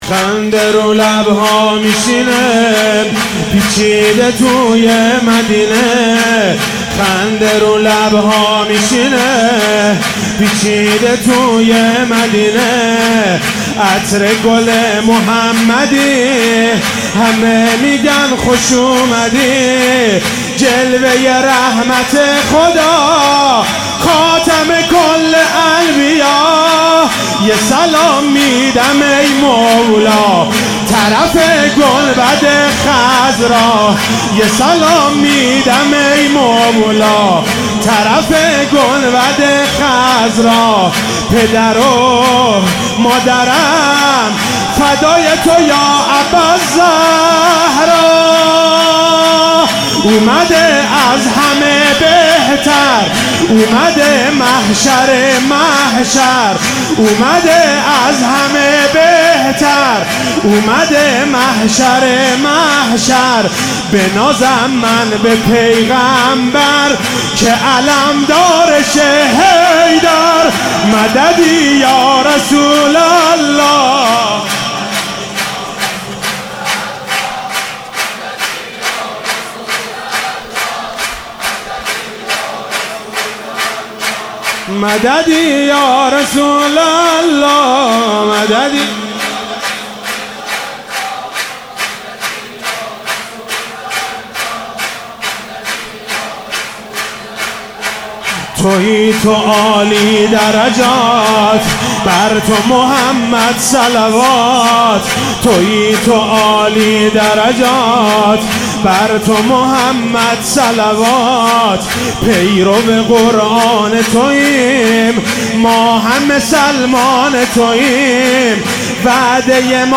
سرود - خنده رو لبها میشینه